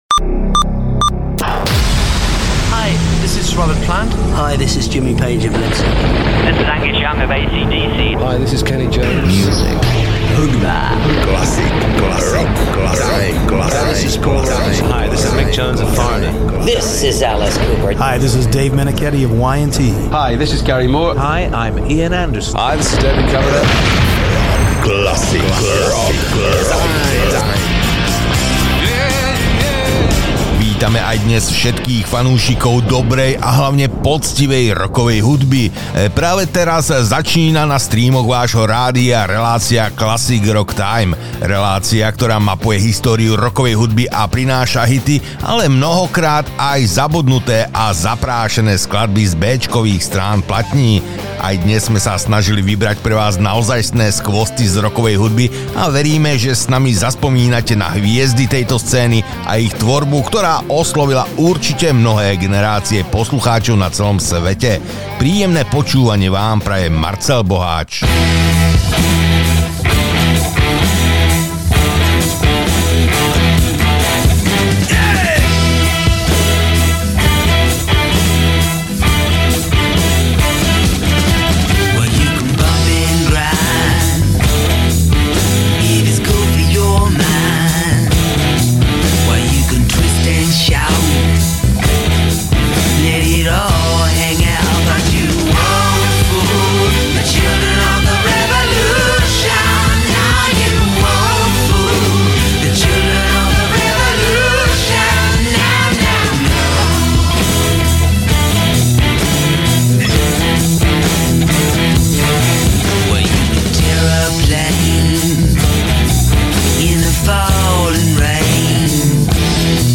Rocková show plná hitov a nezabudnuteľných skladbieb.